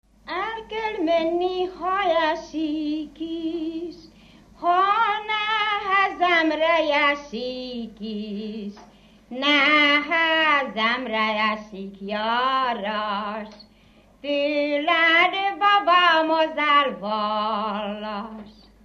Dunántúl - Somogy vm. - Hedrehely
ének
Műfaj: Lakodalmas
Stílus: 1.1. Ereszkedő kvintváltó pentaton dallamok